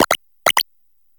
Cri de Compagnol dans Pokémon HOME.